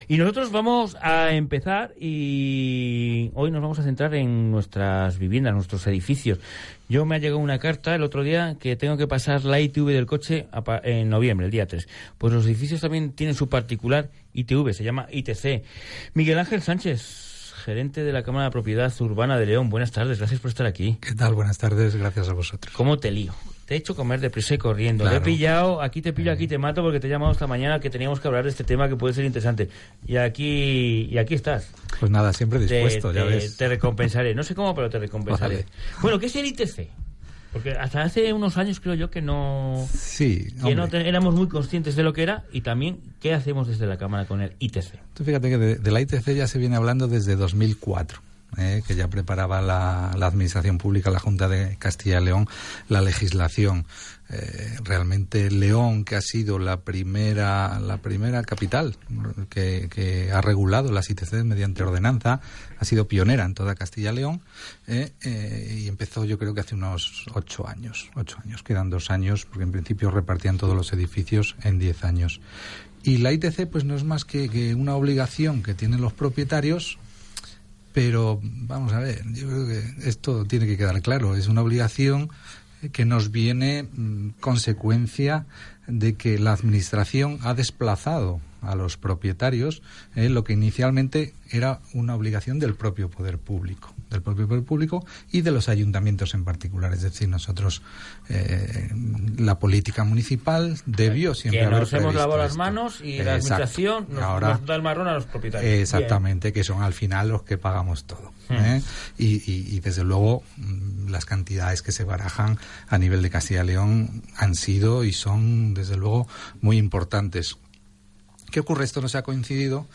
Programa de radio sobre las Inspecciones T�cnicas de Edificios